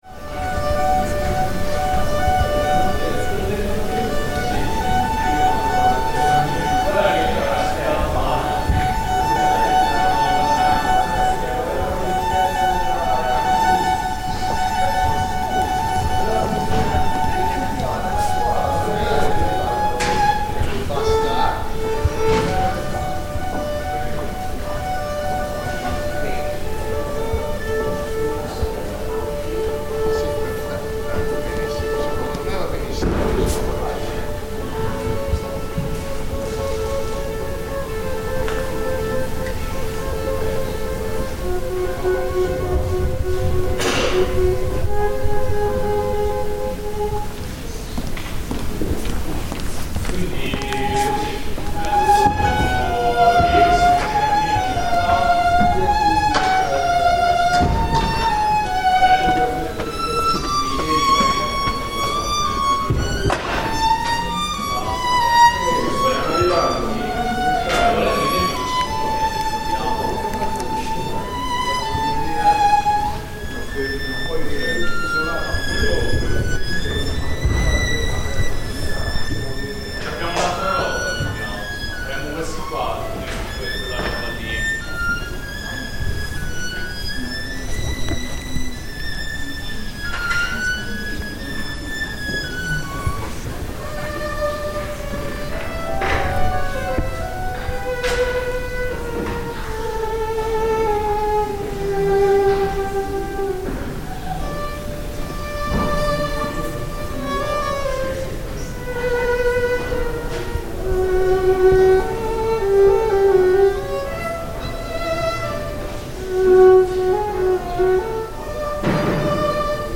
Violin practice inside La Fenice
Inside the world-famous La Fenice theatre in Venice, we visit the opulent, breathtaking main auditorium, when a single violinist comes onto the stage, and begins to tune up and practice ahead of the New Year's Day (Capodanno) concert, which is broadcast on national TV in Italy traditionally every year. A rare chance to hear some intimate, small music on a very grand stage.
Recorded on 29 December 2025 in Venice by Cities and Memory.